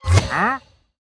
Minecraft Version Minecraft Version latest Latest Release | Latest Snapshot latest / assets / minecraft / sounds / mob / wandering_trader / disappeared1.ogg Compare With Compare With Latest Release | Latest Snapshot
disappeared1.ogg